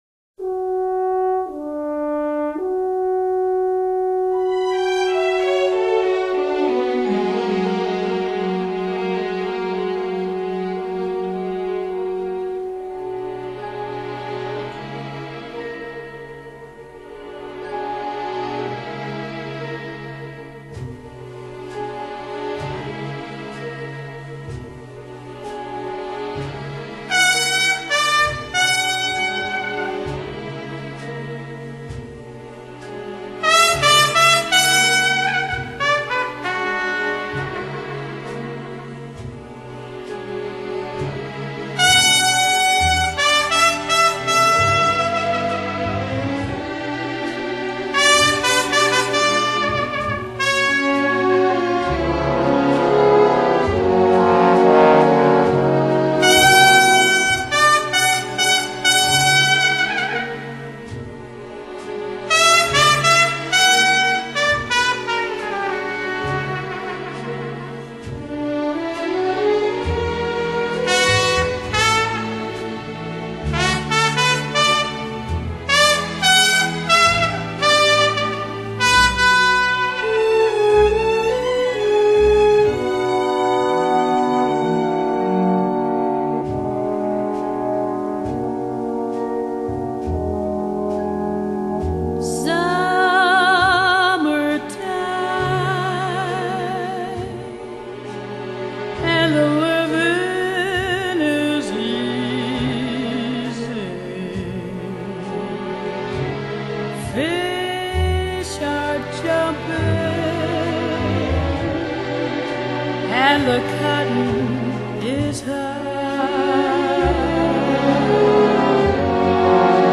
Genre: Jazz, Vocal Jazz